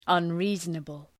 Shkrimi fonetik {ʌn’ri:zənəbəl}